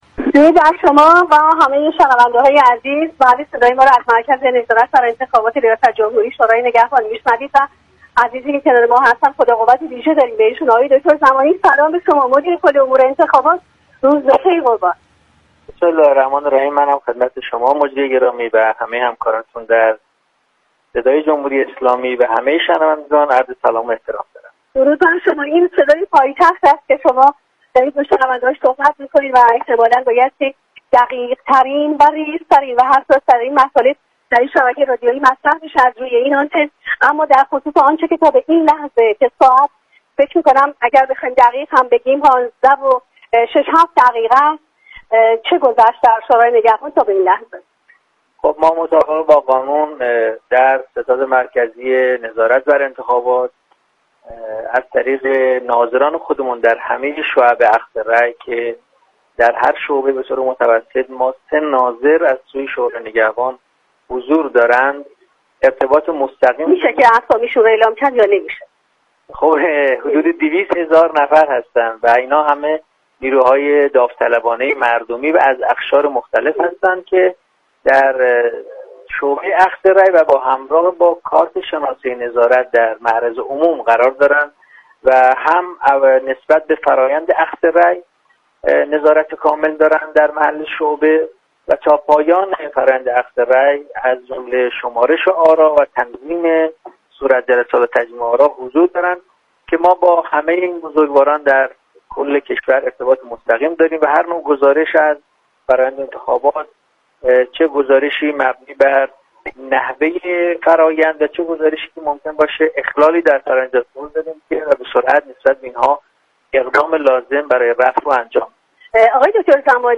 شورای نگهبان در هر شعبه اخذ رأی، سه ناظر دارد به گزارش پایگاه اطلاع رسانی رادیو تهران، امین‌الله زمانی مدیركل انتخابات شورای نگهبان در گفت و گو با ویژه برنامه انتخاباتی «شهرآرا» اظهار داشت: در هر شعبه اخذ رأی به طور متوسط سه ناظر از سوی شورای نگهبان حضور دارند.